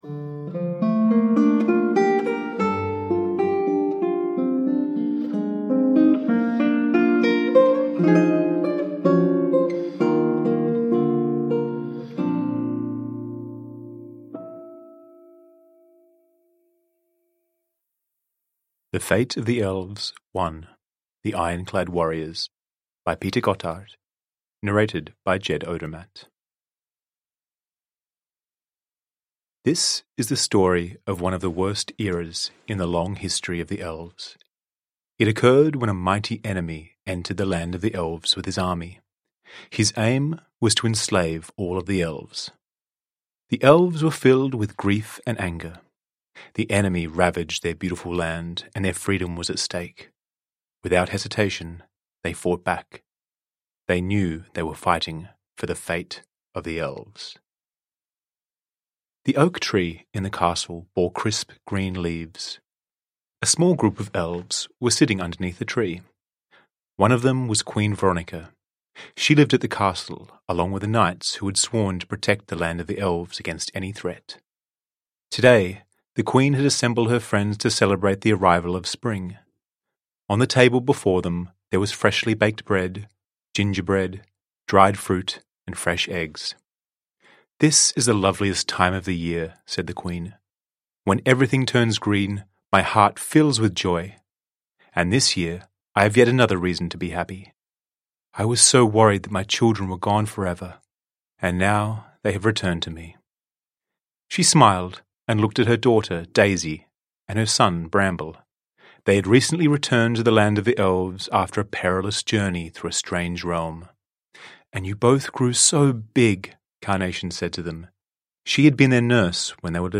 The Fate of the Elves 1: The Ironclad Warriors / Ljudbok